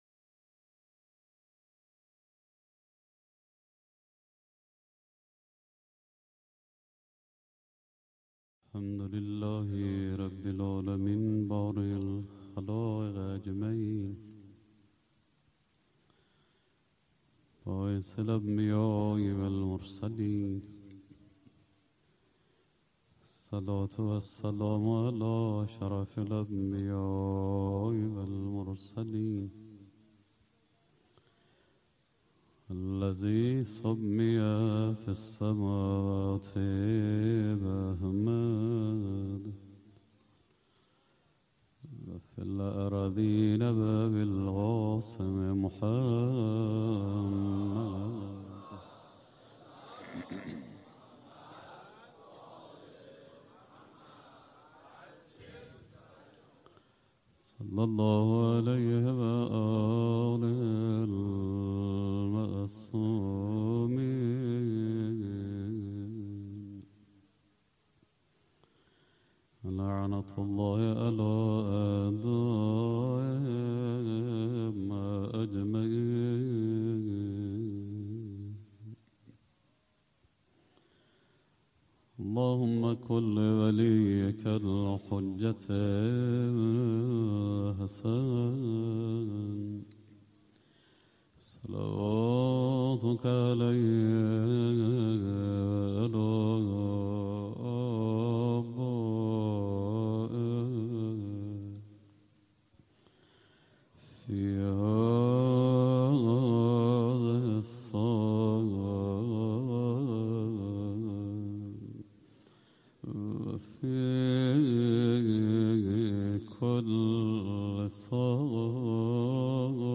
روز اول محرم 96 - موسسه انصار الحسین (ع) - سخنرانی